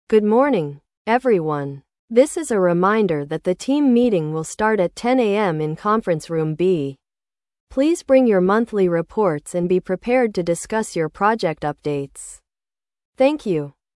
（TOEIC Part4形式の設問）
質問: 音声の女性話者は、主に何について案内していますか？
toeic_listening_practice.mp3